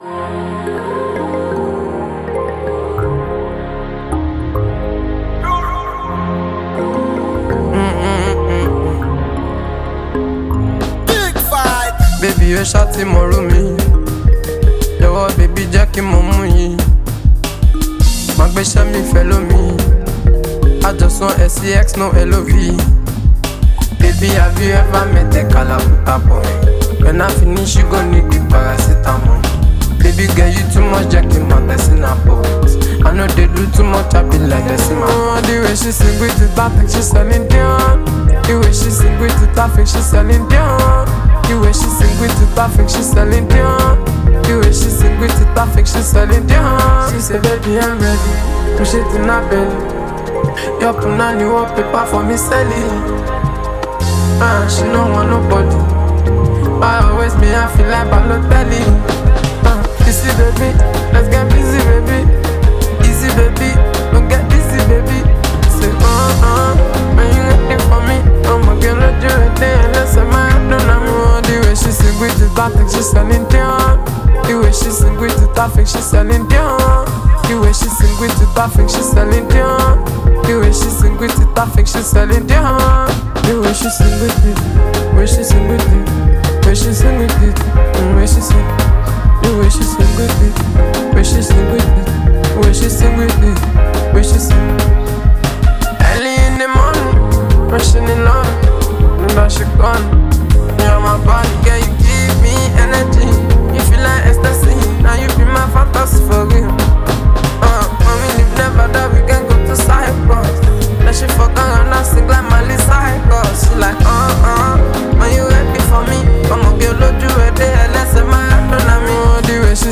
Renowned Nigerian Afrobeats talent and performer
an inspiring vibe
The music scene is excited to embrace this energetic release